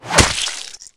monsterclaw.wav